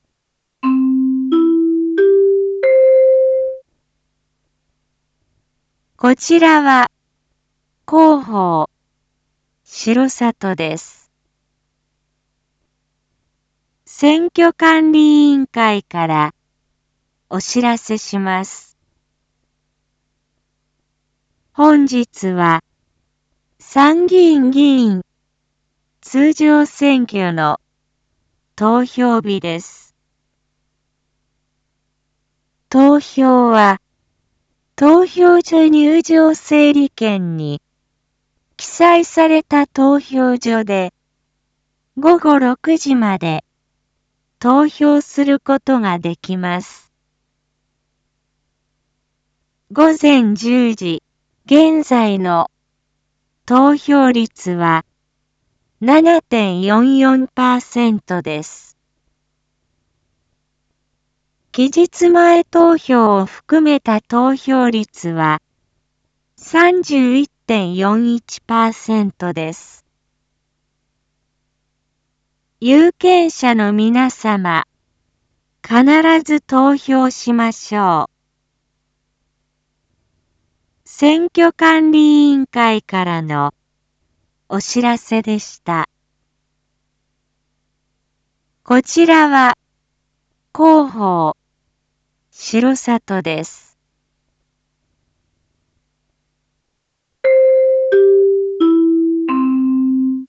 Back Home 一般放送情報 音声放送 再生 一般放送情報 登録日時：2022-07-10 10:21:41 タイトル：R4.7.10 参議院議員通常選挙（午前１０時現在投票状況） インフォメーション：こちらは広報しろさとです。